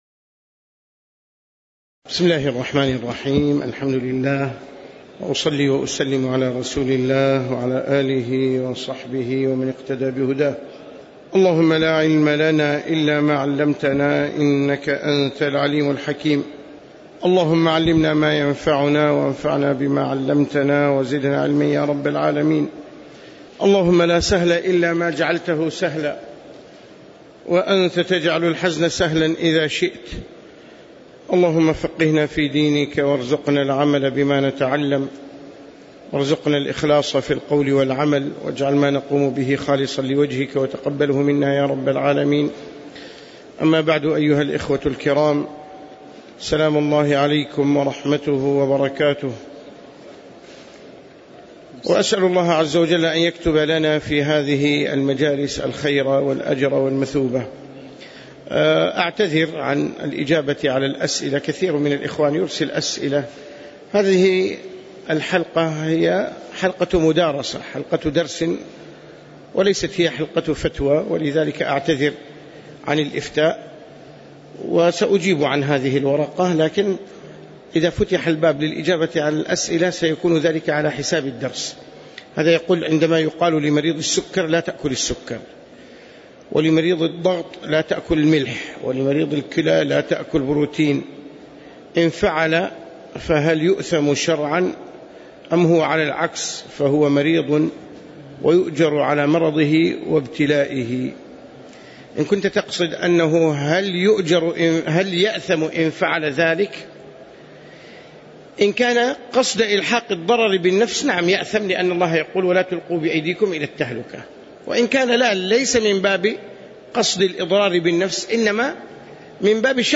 تاريخ النشر ١٦ ربيع الأول ١٤٣٩ هـ المكان: المسجد النبوي الشيخ